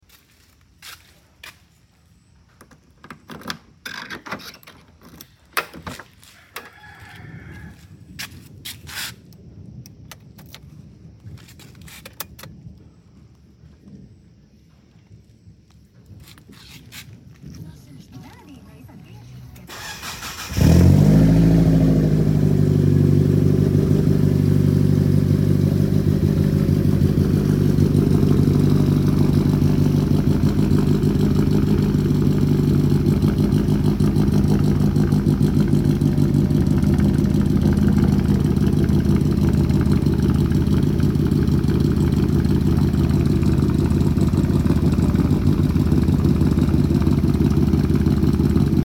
Belanja sikit cool start kereta sound effects free download